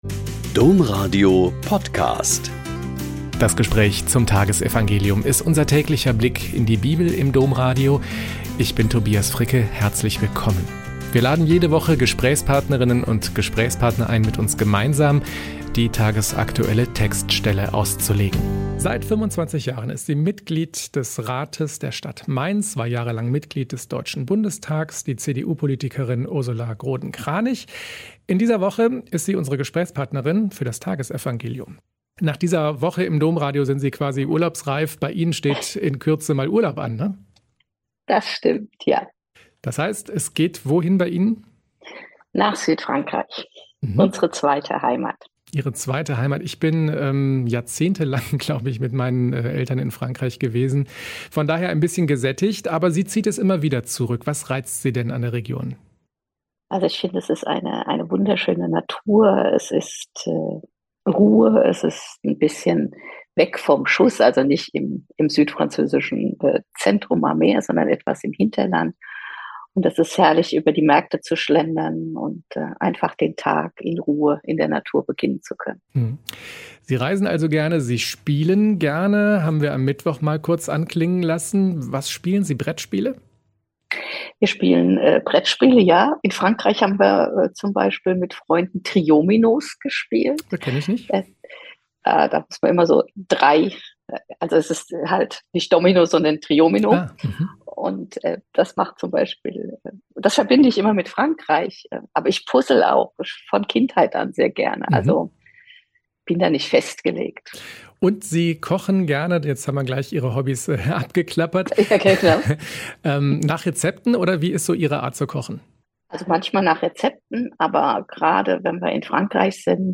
Joh 21,1.15-19 - Gespräch mit Ursula Groden-Kranich